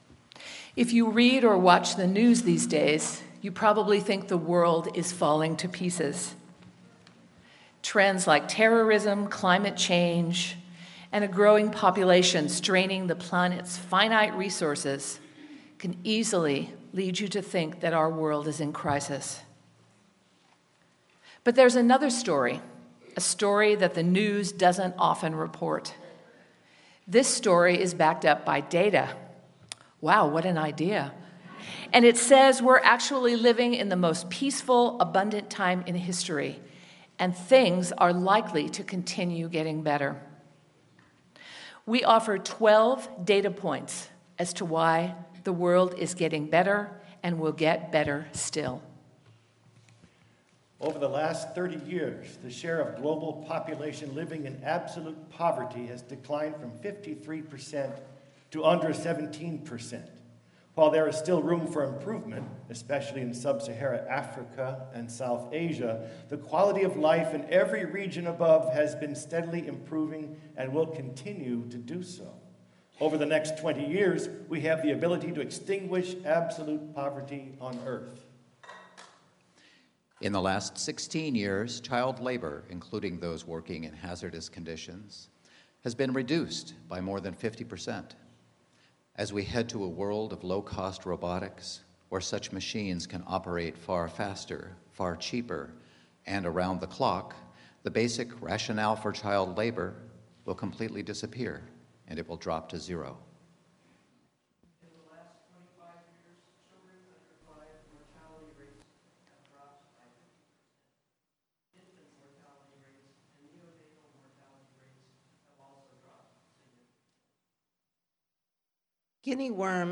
Unitarian Universalist Society of Sacramento